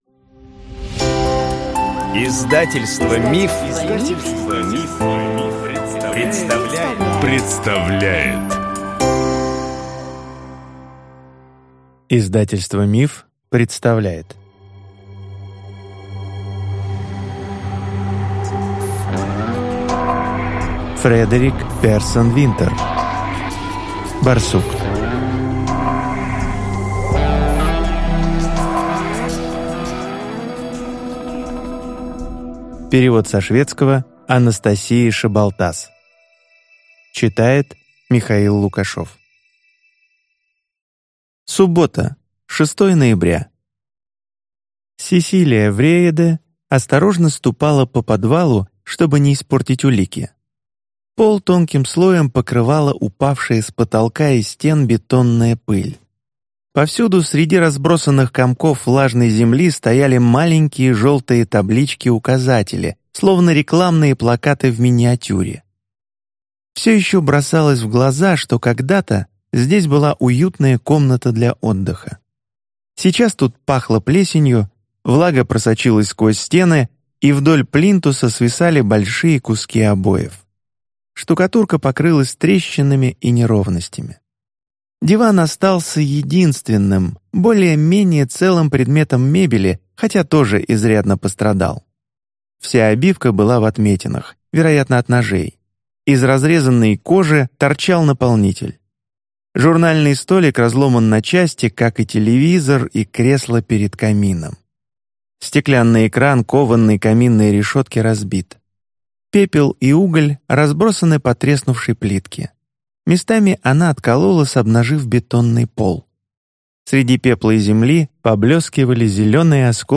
Аудиокнига Барсук | Библиотека аудиокниг